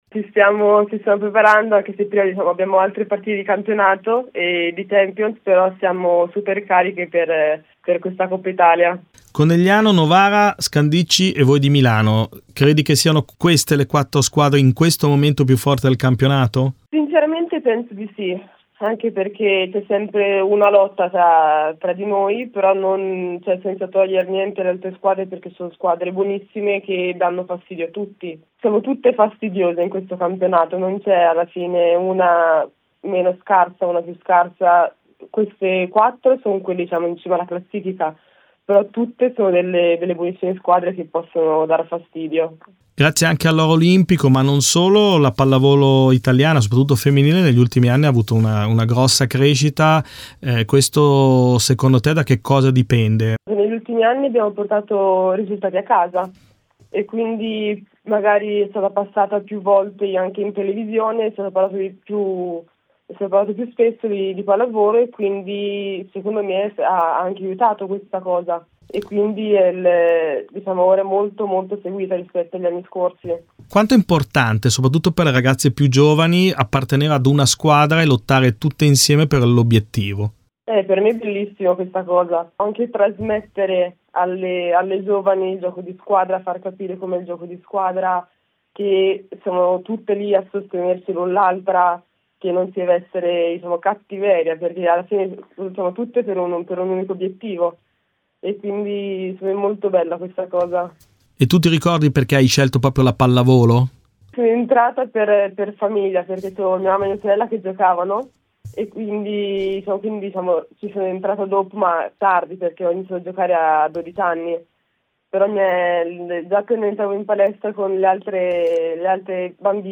Ai microfoni di Radio Bruno le voci di alcune delle protagoniste delle squadre che scenderanno in campo per la vittoria finale.